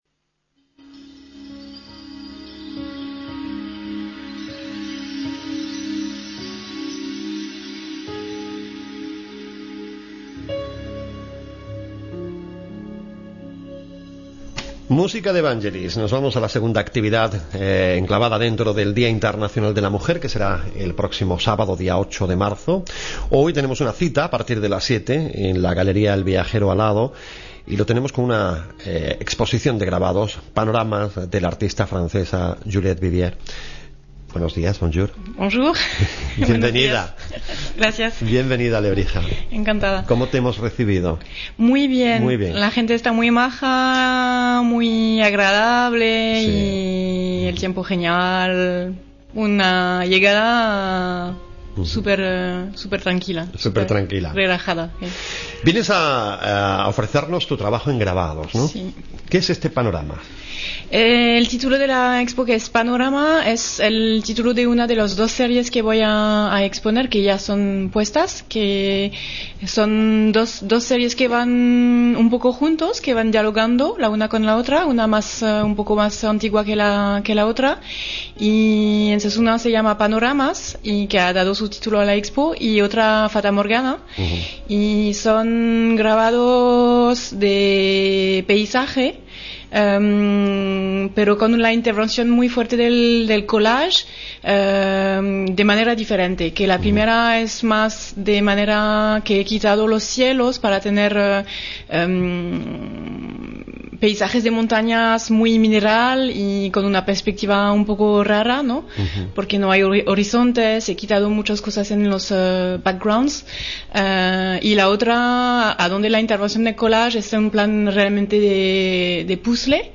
Interview Radio Lebrija 06.03.2014
ENTREVISTA.mp3